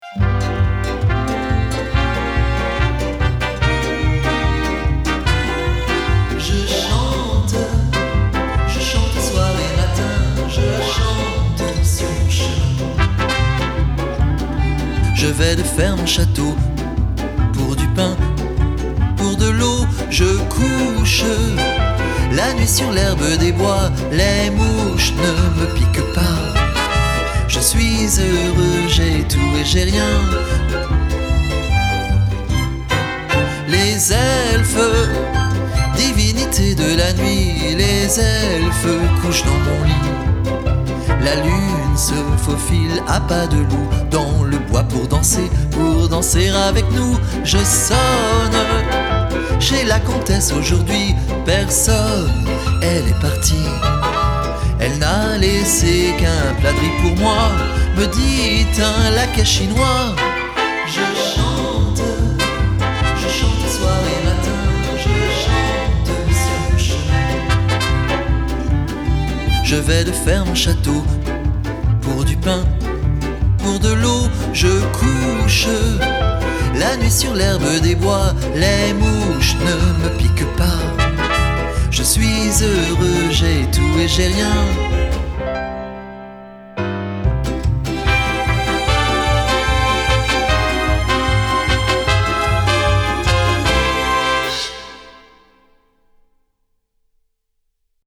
La version chantée avec les trous (Facile)